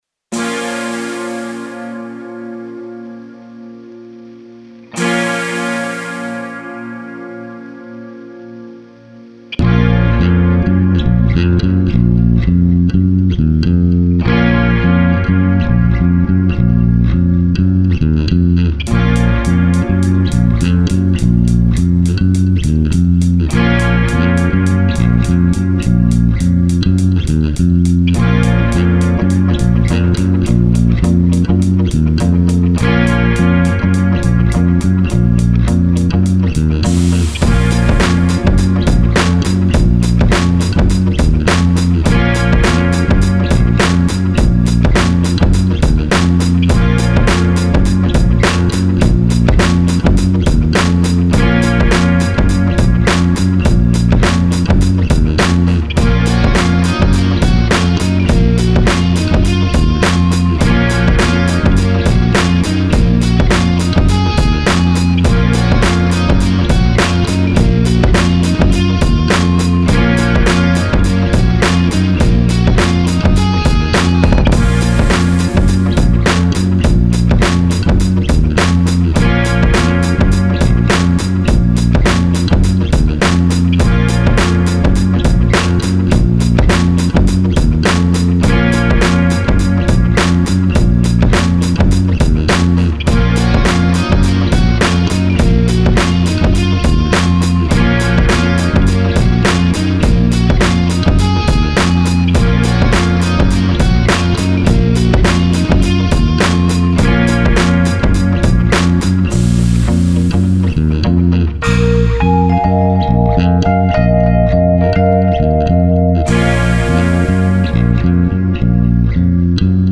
HipHop Bass Song
hiphop-song.mp3